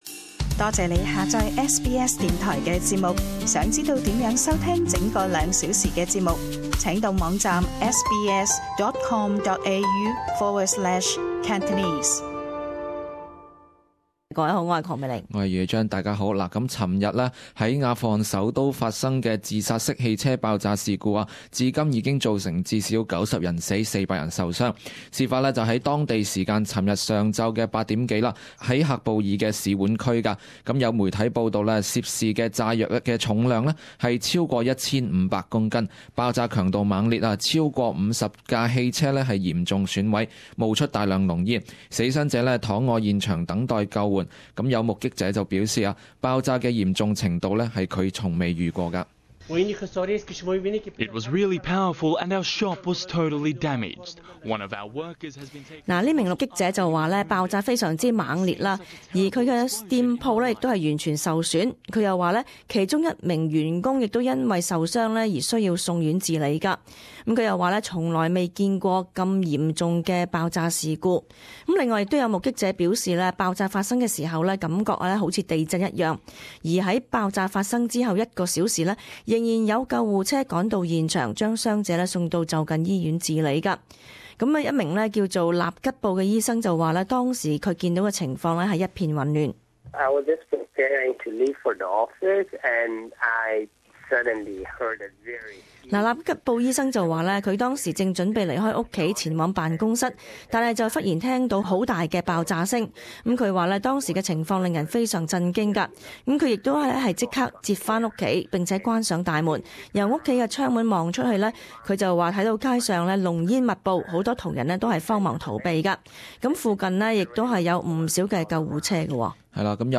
【时事报导】阿富汗使馆区恐袭近 500 死伤